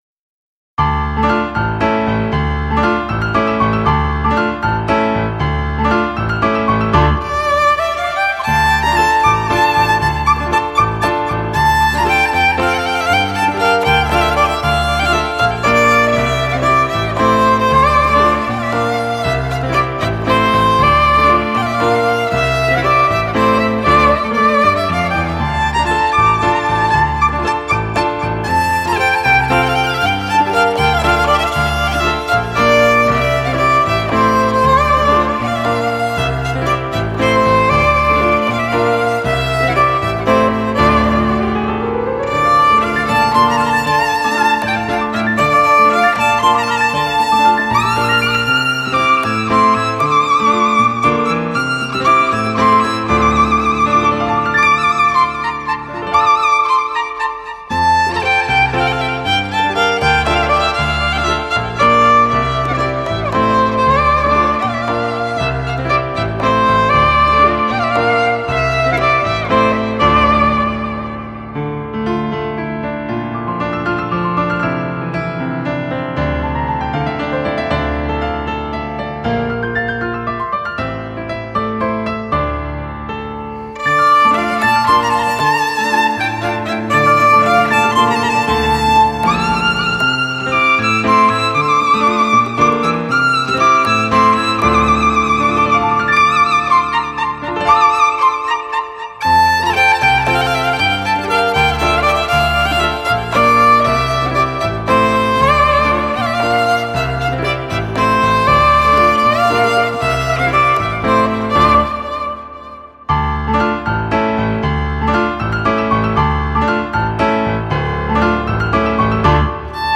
۳. آواز سنتی و فولکلوریک (Traditional / Folk Vocal)